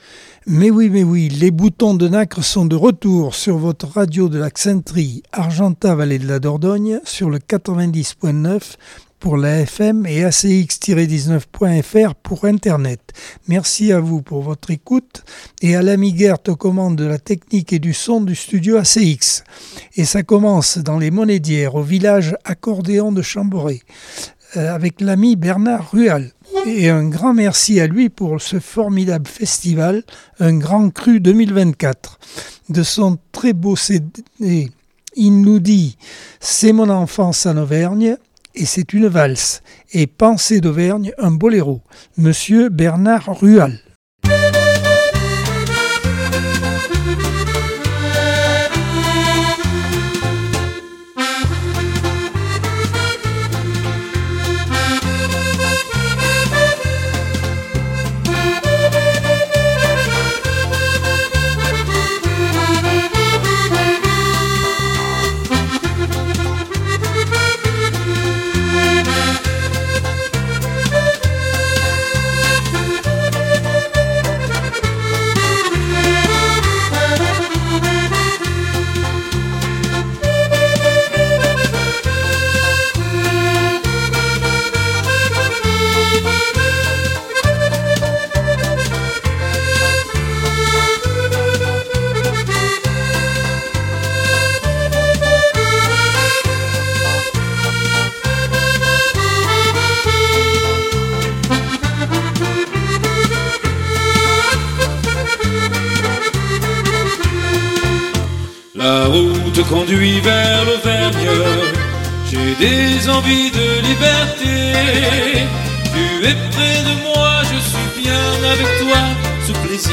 Accordeon 2024 sem 46 bloc 1 - Radio ACX